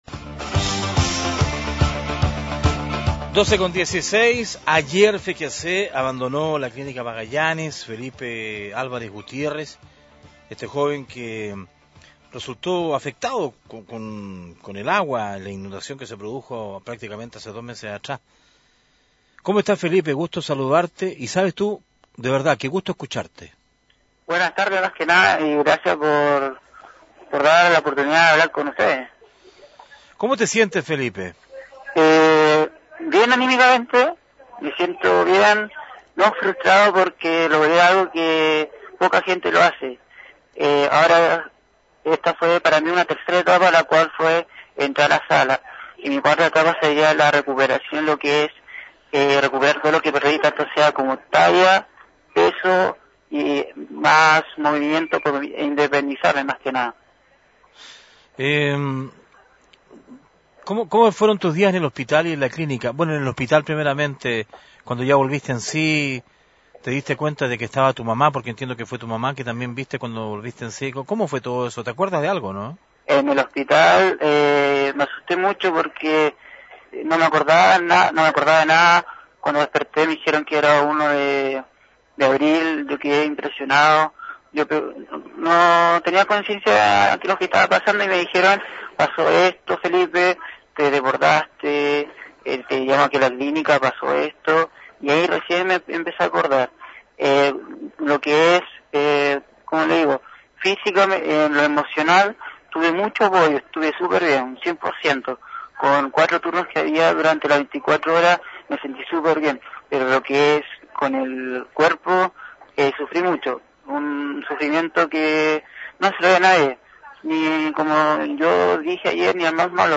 Entrevistas de Pingüino Radio - Diario El Pingüino - Punta Arenas, Chile
Eduardo Chihuailaf,cónsul chileno C.Rivadavia